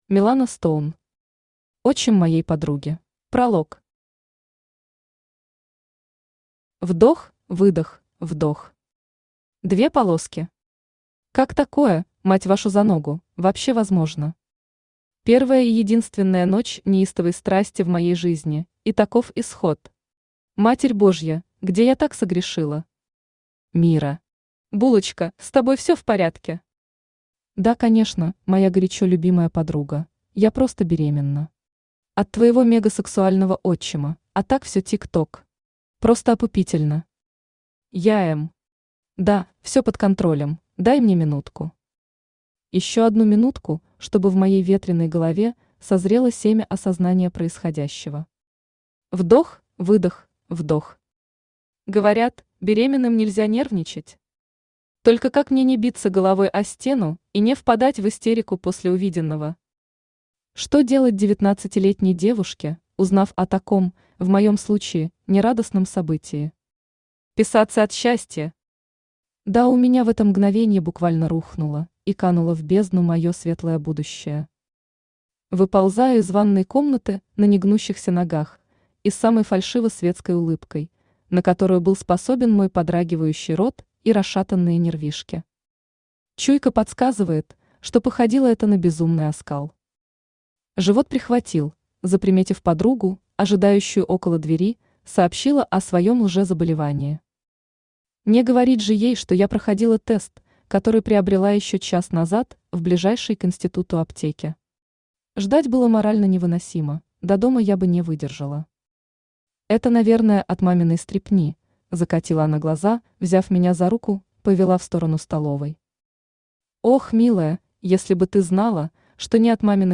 Аудиокнига Отчим моей подруги | Библиотека аудиокниг
Aудиокнига Отчим моей подруги Автор Милана Стоун Читает аудиокнигу Авточтец ЛитРес.